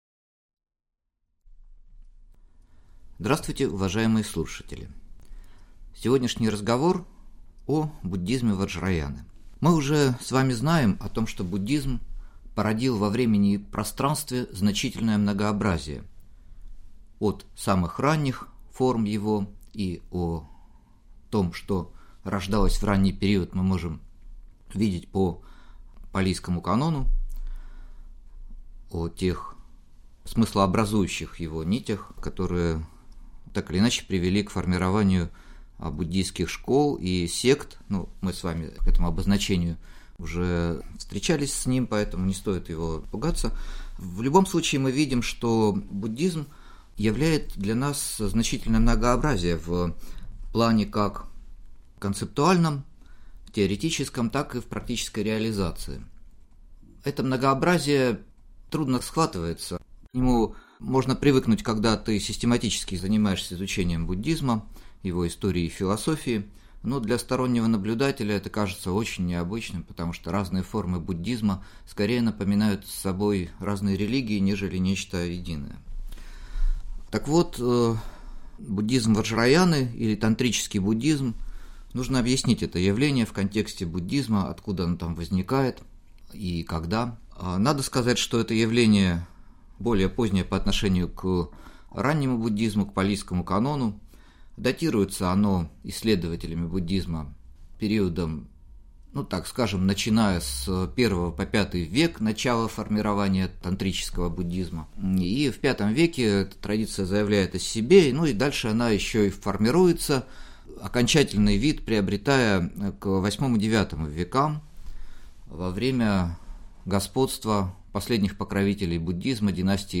Аудиокнига Буддизм ваджраяны | Библиотека аудиокниг